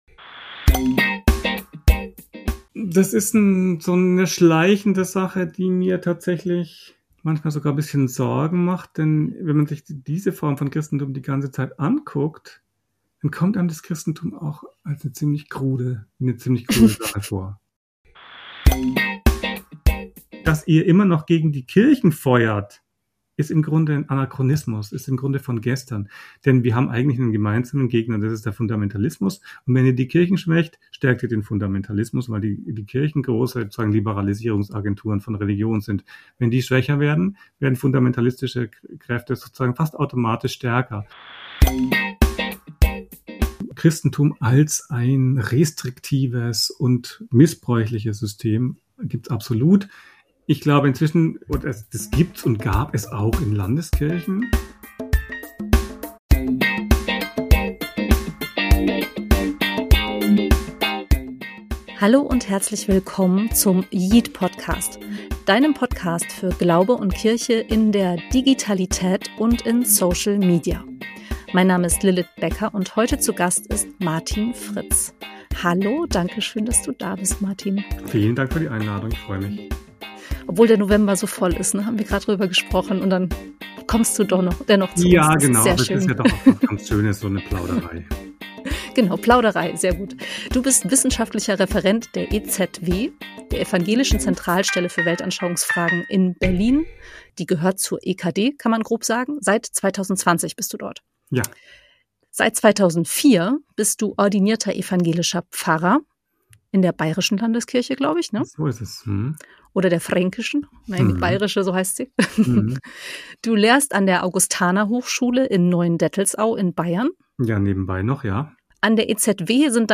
Social Media für Glaube und Kirche - das ist der yeet-Podcast: yeet-Redakteur* innen befragen Expert* innen und Influencer* innen und begeben sich auf die Suche nach den großen und kleinen Perspektiven auf die digitalen Kirchen-Räume und Welten in den Sozialen Medien.